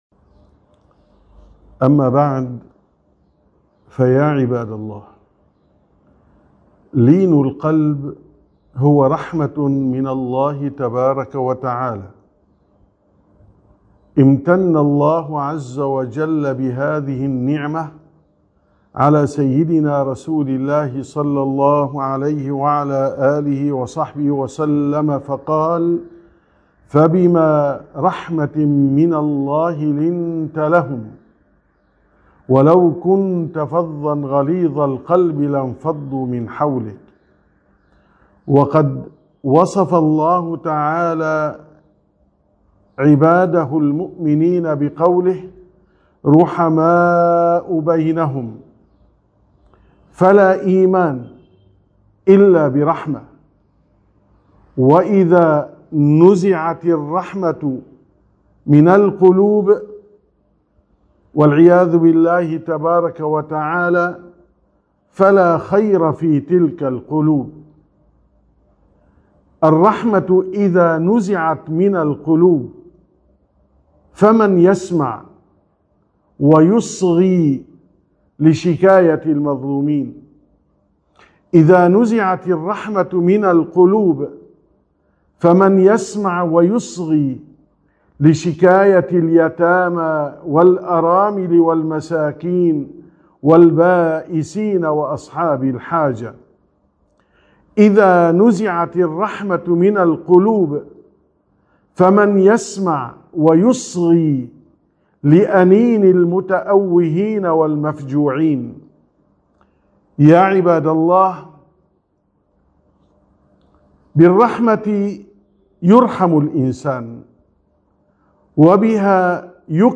943ـ خطبة الجمعة: هل تشعر بآلام الآخرين؟